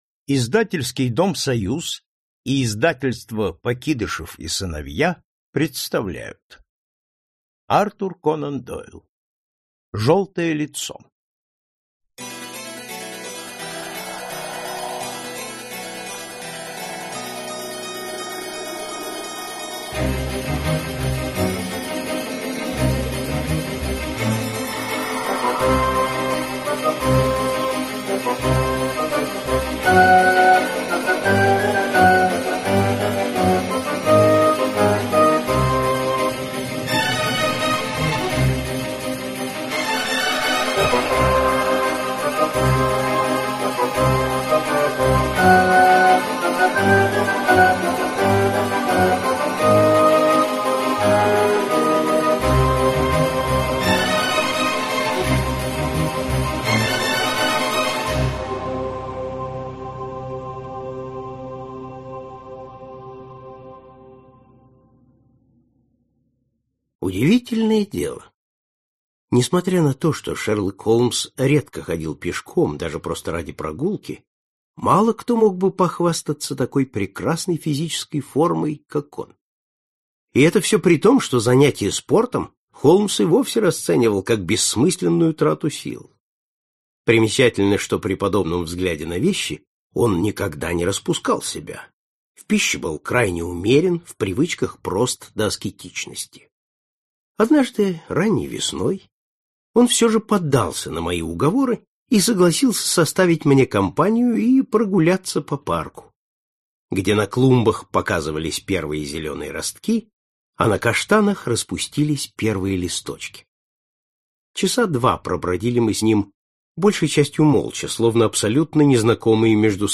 Аудиокнига Желтое лицо | Библиотека аудиокниг